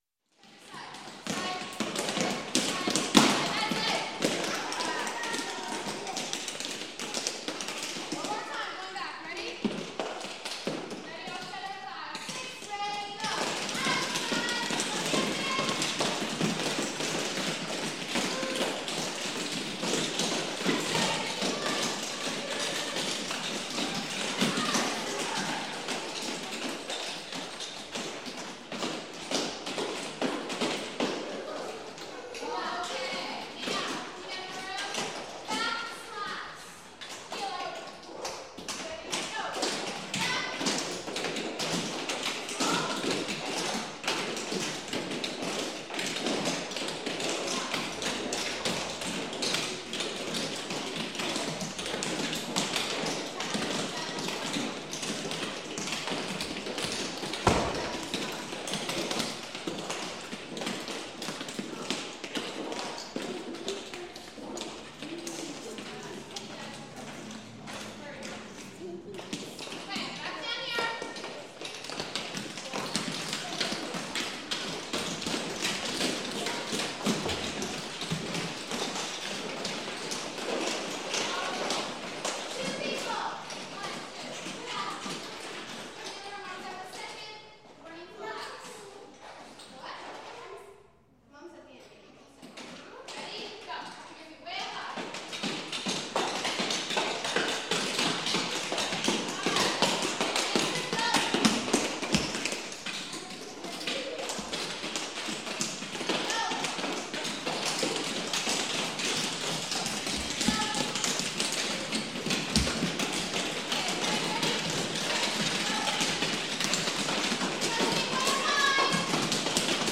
Звуки чечётки
Звуки тренировки в танцевальном зале учат чечётку